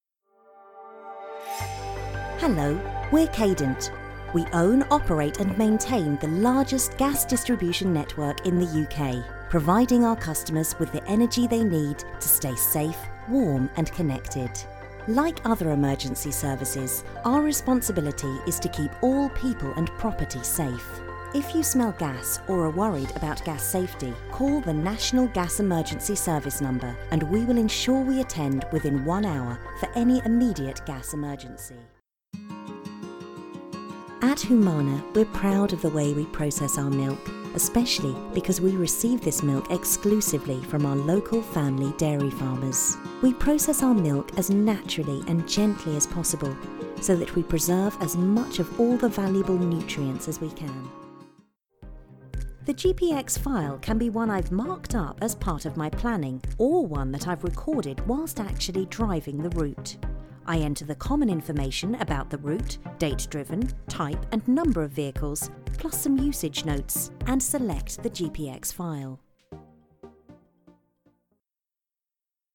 English (British)
Natural, Playful, Versatile, Friendly, Warm
Corporate